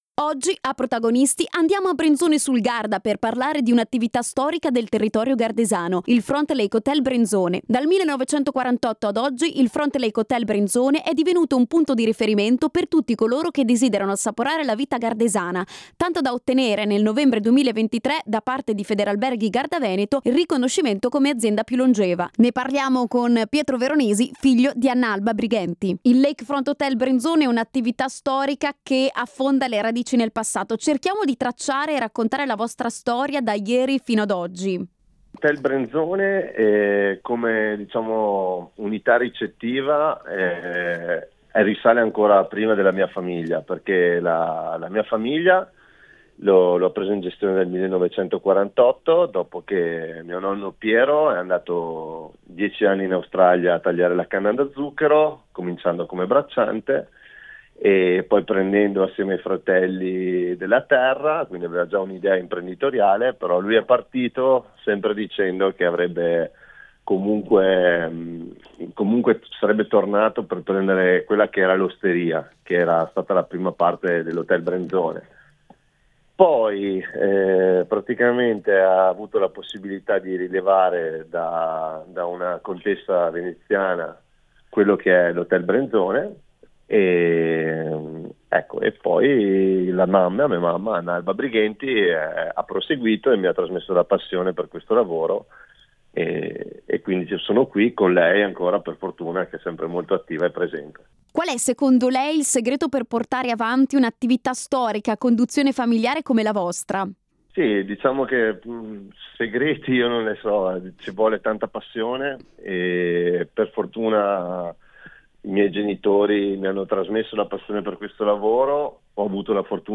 intervista-estesa-hotel.mp3